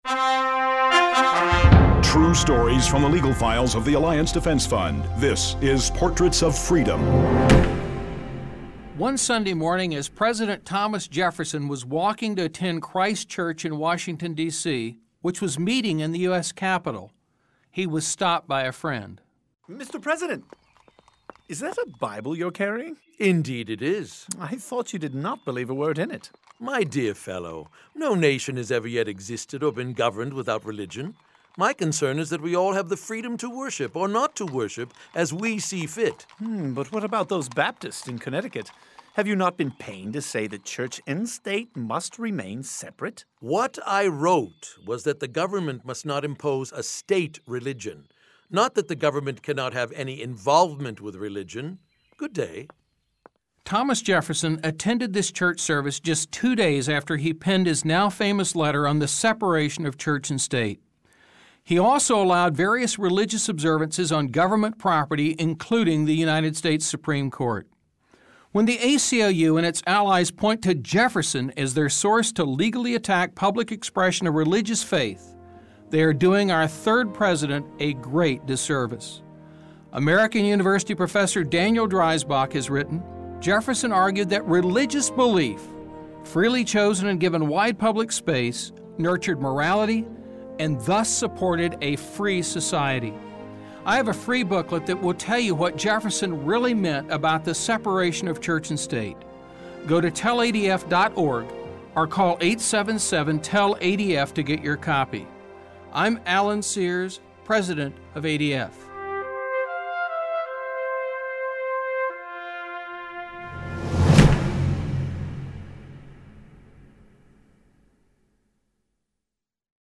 Alliance Defense Fund radio clip: